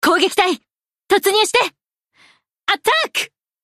Ship Voice Lexington Attack.mp3
Ship_Voice_Lexington_Attack.mp3.ogg